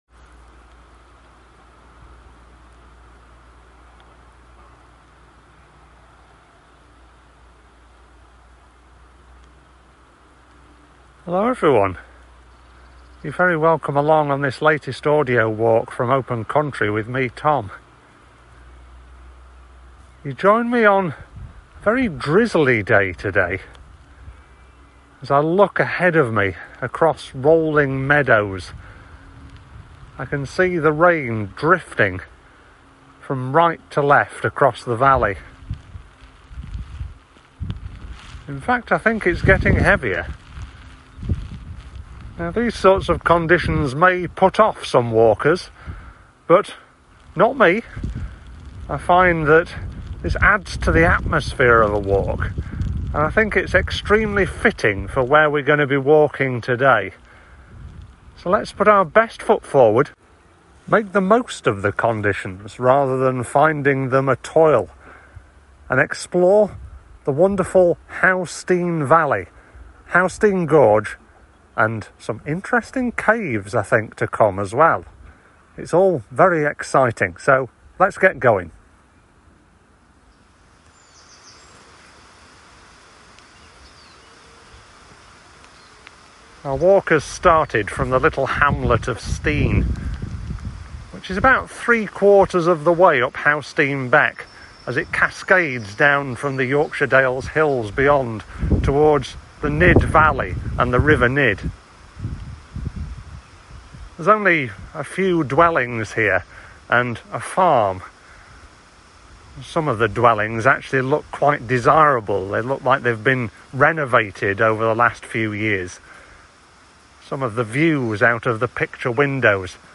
How Stean Gorge Audio Walk